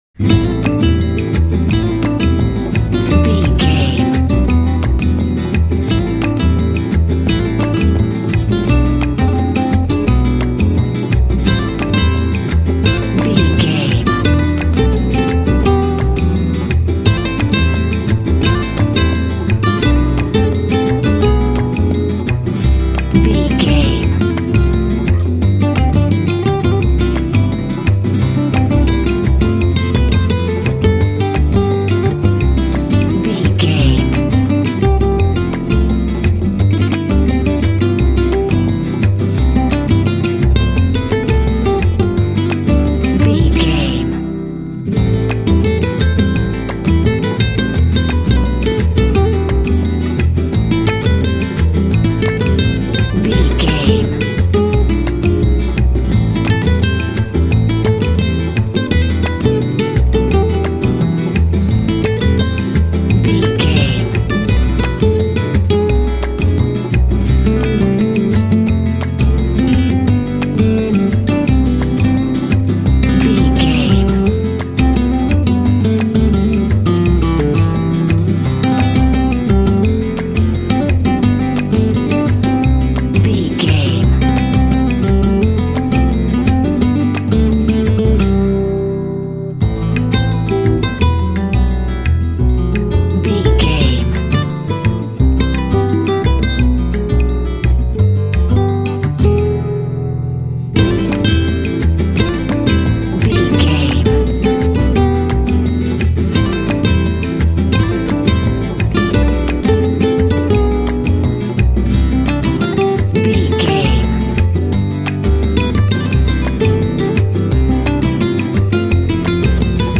Aeolian/Minor
sexy
smooth
sensual
acoustic guitar
percussion
double bass
latin
flamenco
mambo
rhumba